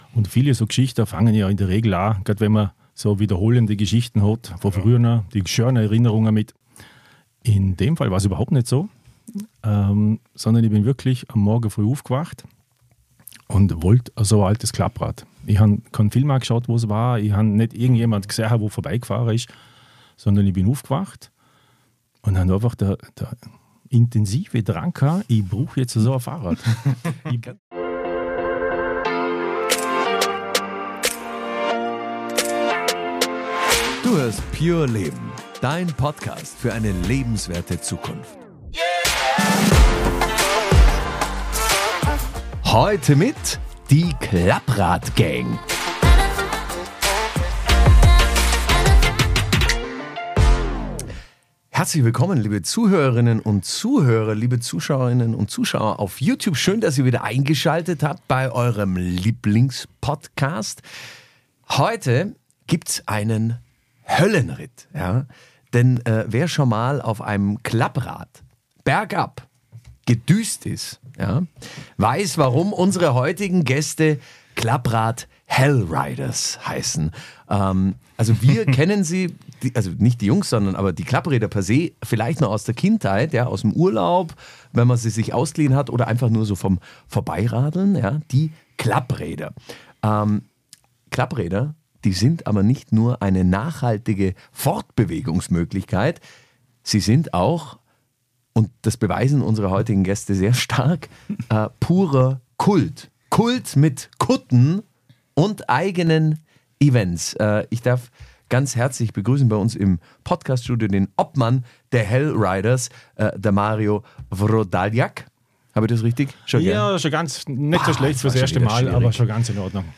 Besonders spannend wird’s im Gespräch mit zwei echten Helden aus der Szene